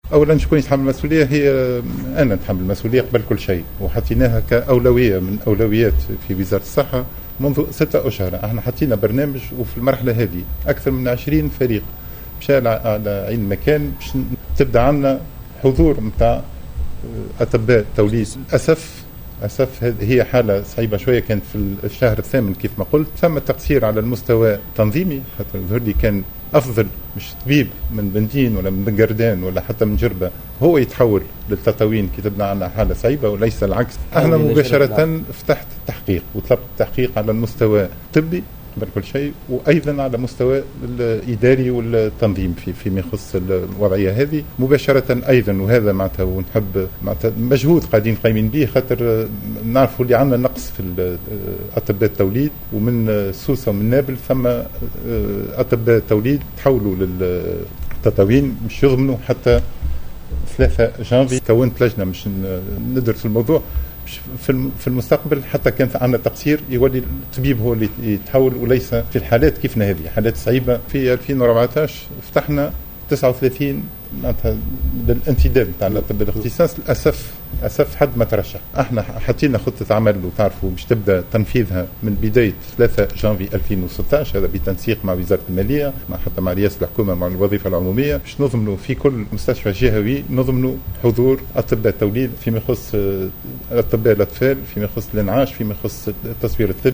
Le ministre de la santé Said Aïdi a indiqué lors d’une déclaration accordée à El Watanya 1, qu’il assume la responsabilité du décés de la femme enceinte à Tataouine.